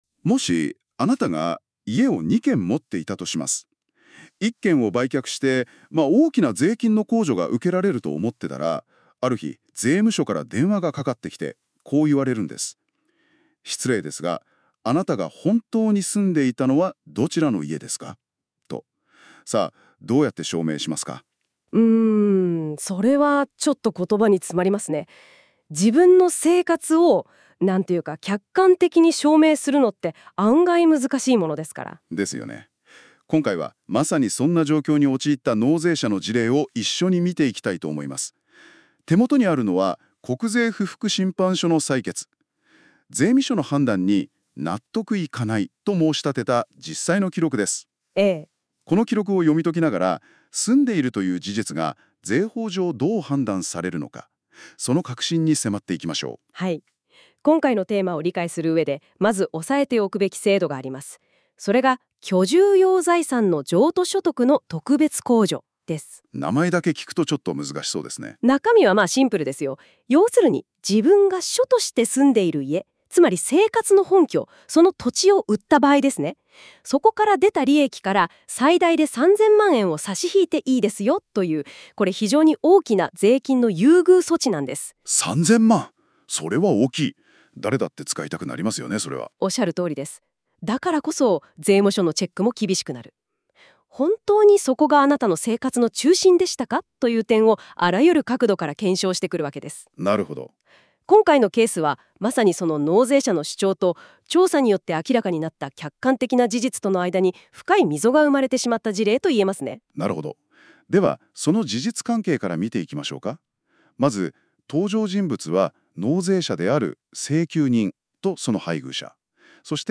※国税不服審判所の採決を元データとしてＡＩに音声解説を作成してもらいました。